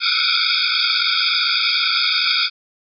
이명소리의 종류
이명소리 3
▶ 누르면 해당 이명소리가 들립니다.